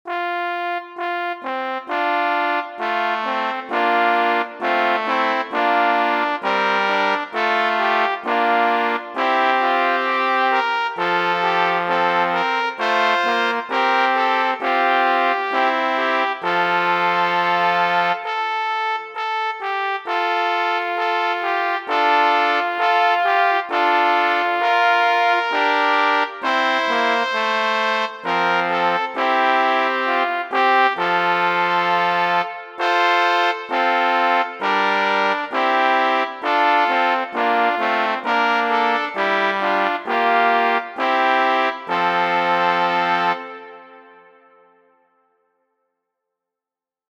6.F Duette, Trios, Quartette in F-Dur